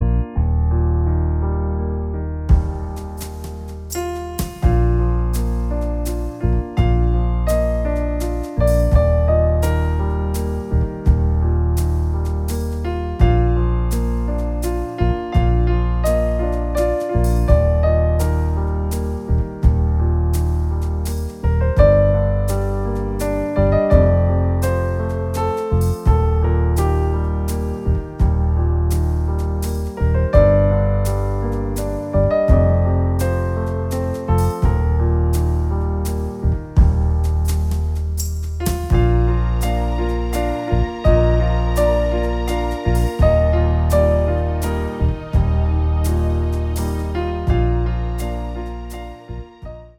一、体育舞蹈(标准舞)：
2、华尔兹：